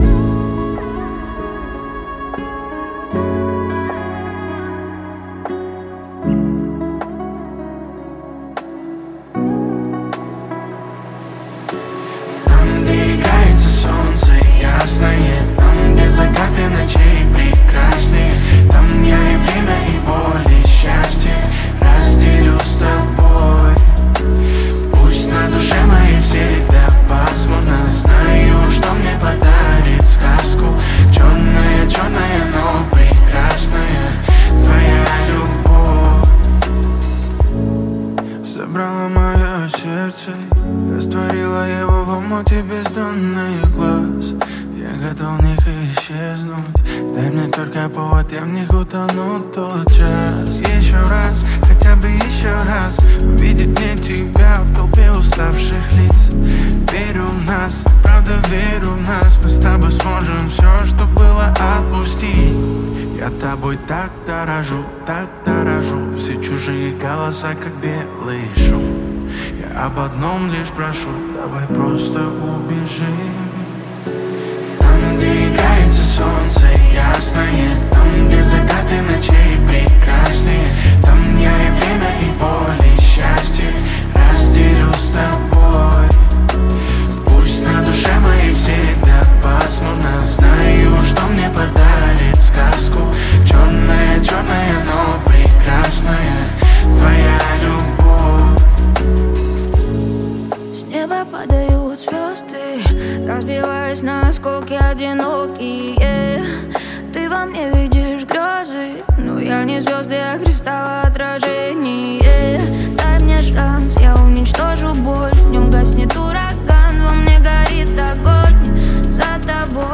• Жанр песни: Жанры / Поп-музыка